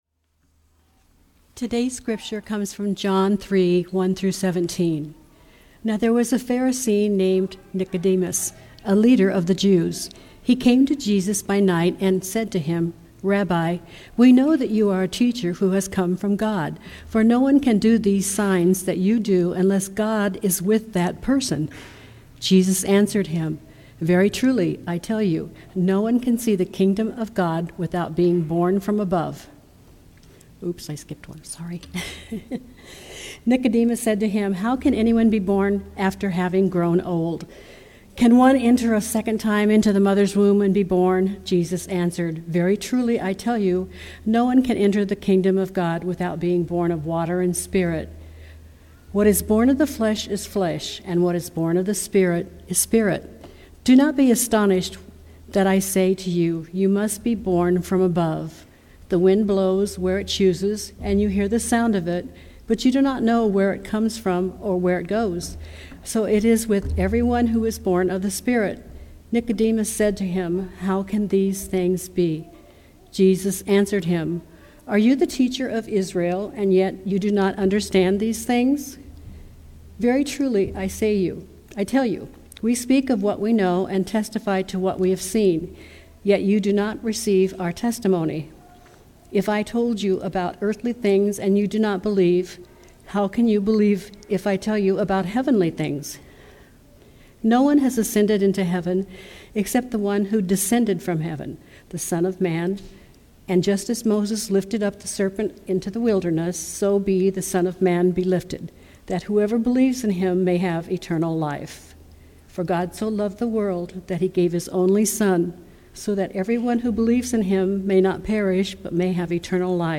Sermons | Broadway United Methodist Church